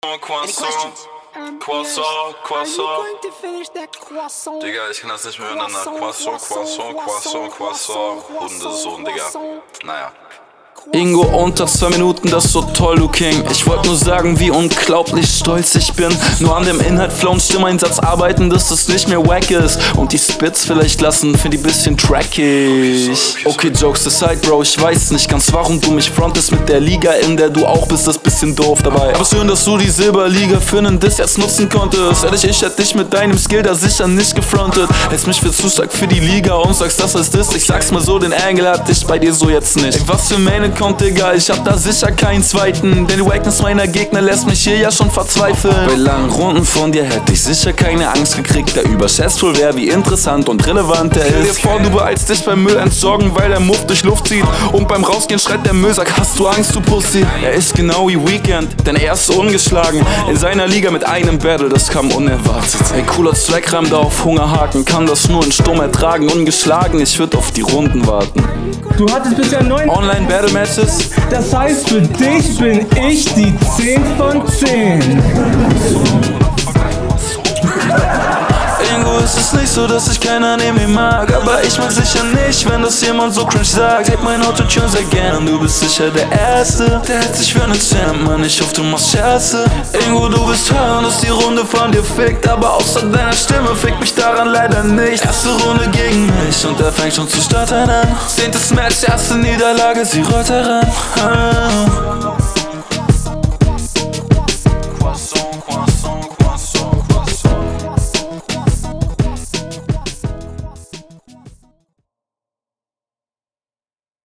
Mix ist bisschen weird. Abseits davon klingt das aber sehr rund.